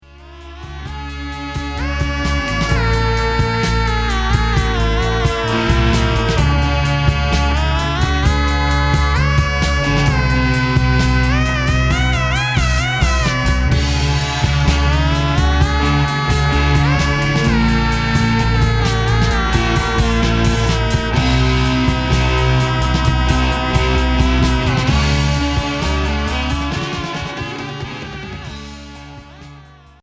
Progressif Premier 45t retour à l'accueil